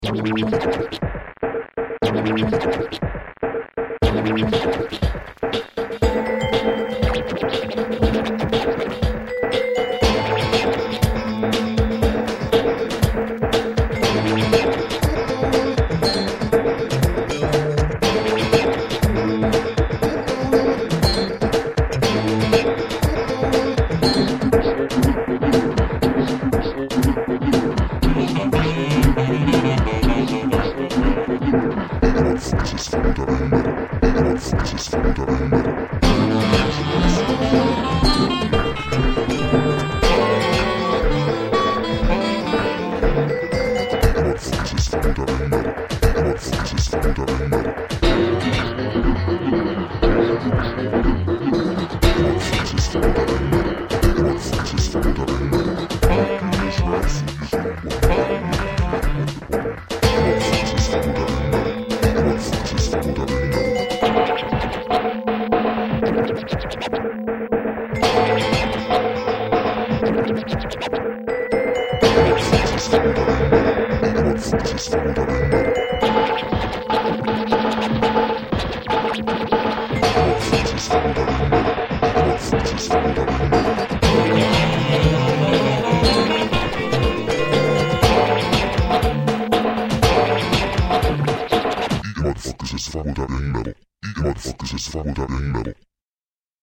funk based loop salad